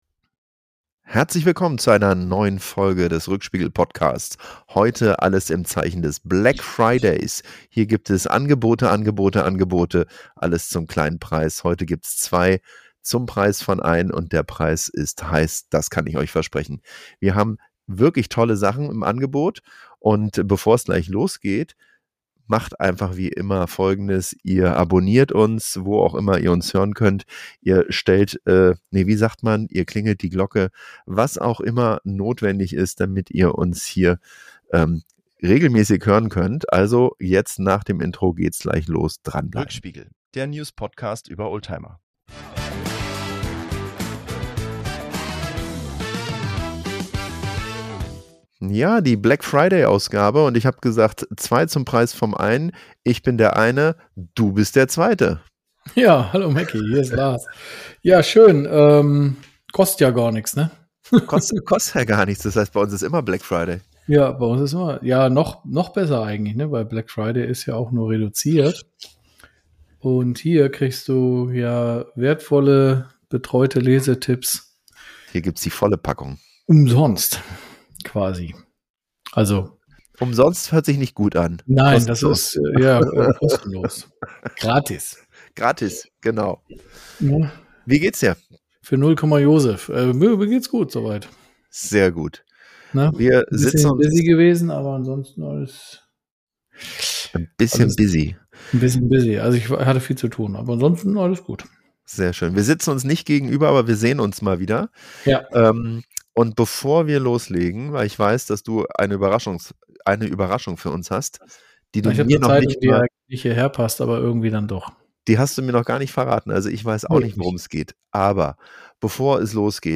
Insgesamt ist es eine lockere, witzige Folge mit vielen Zeitschriften- und Messe-Tipps rund um Tuning, Oldtimer und die Autoszene.